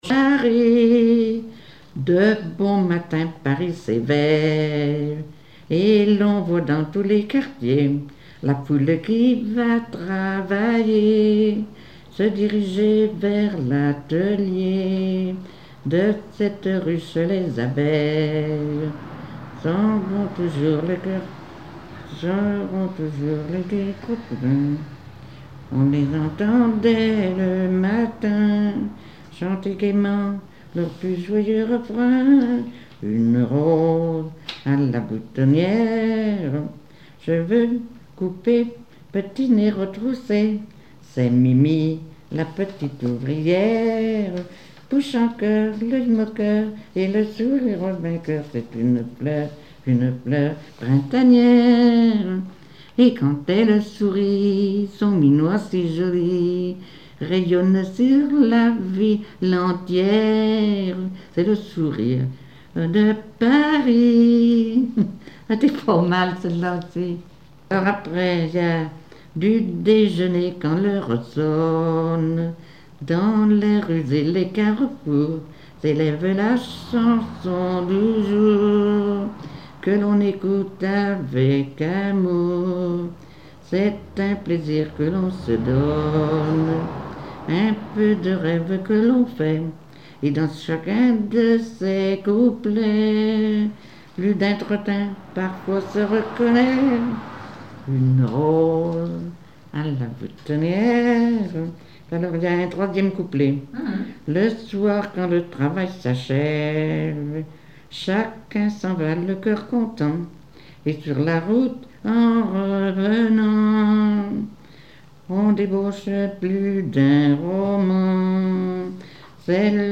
Genre strophique
Enquête Arexcpo en Vendée-Association Joyeux Vendéens
Catégorie Pièce musicale inédite